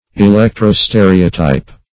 Electro-stereotype \E*lec`tro-ste"re*o*type\